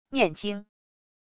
念经wav音频